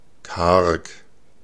Ääntäminen
US : IPA : [ˈbæ.rən] UK : IPA : /ˈbæɹən/